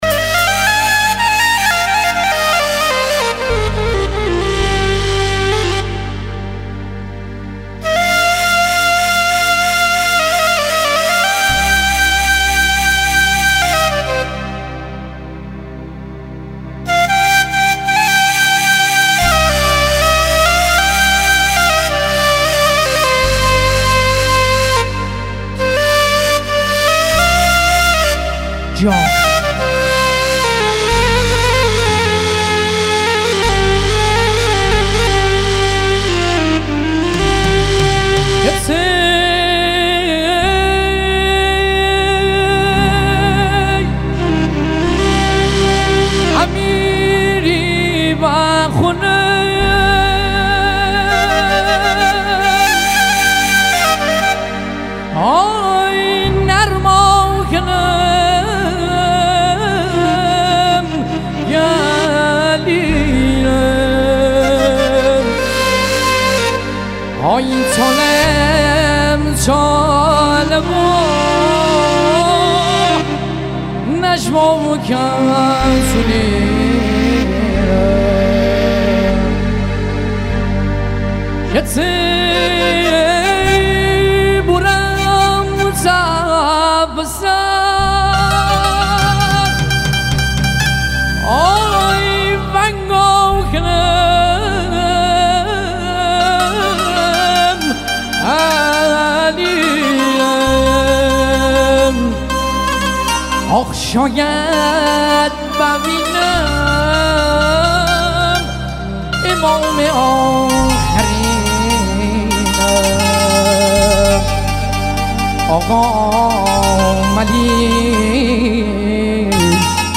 دسته بندی : آهنگ محلی و سنتی